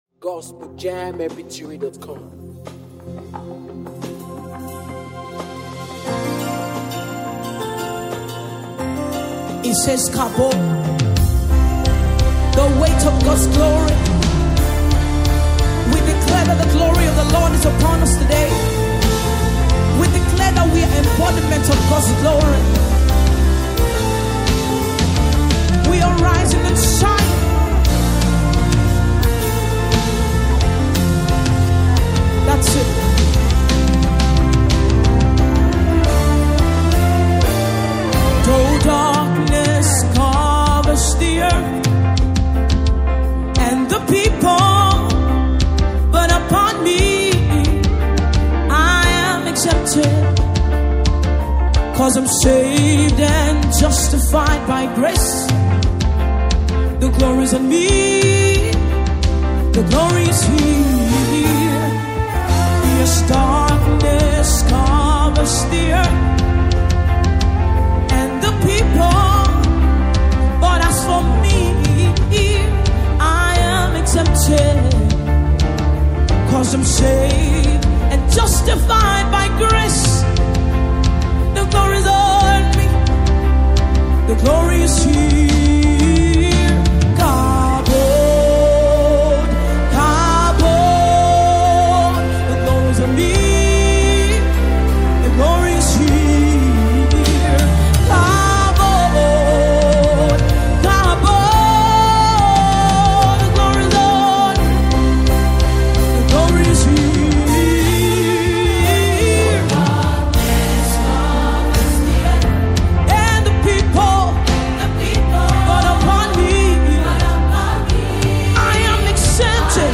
African praisemusicVIDEO